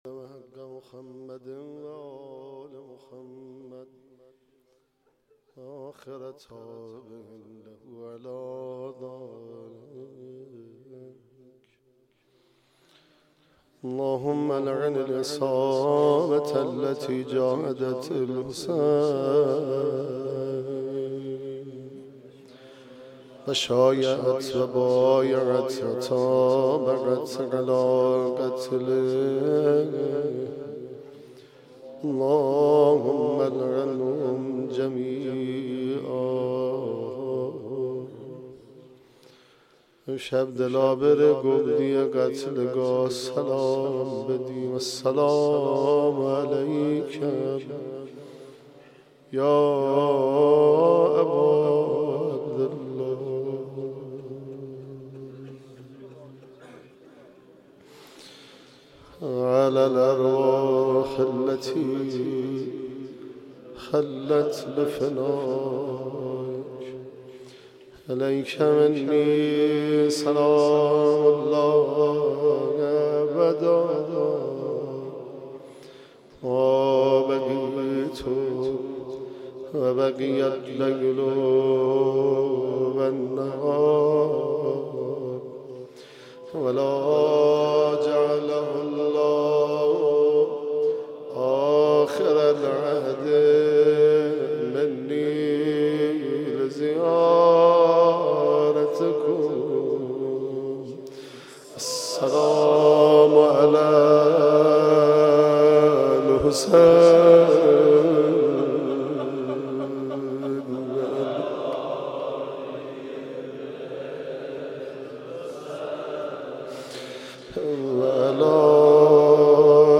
یازدهمین شب از مـــراســم عـــزاداری دهــه اول مـــحــرم الـحــرام
روضه